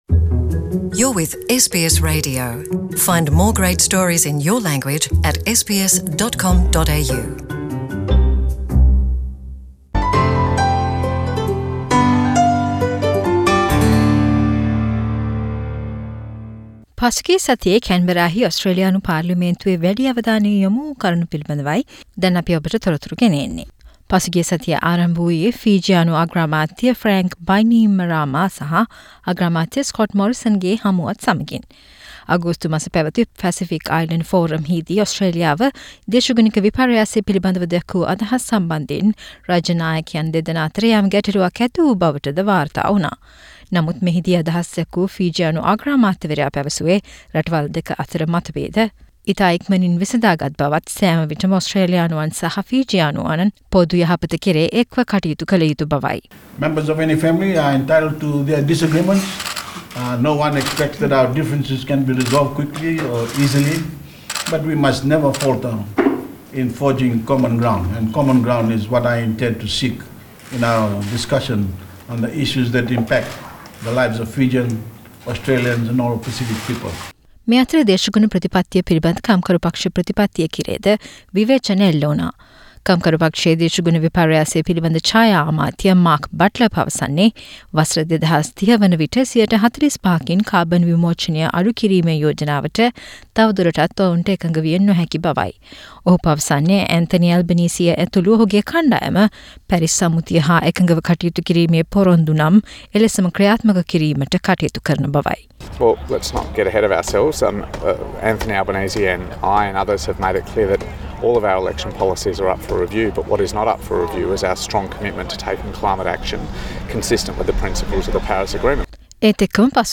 ඔස්ට්‍රේලියානු මධ්‍යම රජයේ අය වැය අතිරික්තය සහ හඳුන්වාදුන් නව පවුල් නීති මෙන්ම ෆිජි අග්‍රාමාත්‍යවරයාගේ ඔස්ට්‍රේලියානු සංචාරය ඇතුළු පසුගිය සතියේ ඔස්ට්‍රේලියානු පාර්ලෙම්න්තුවෙන් ඇසුණු පුවත් සමාලෝචනයක් SBS සිංහල වෙතින්